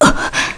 Pavel-Vox_Damage_kr_02.wav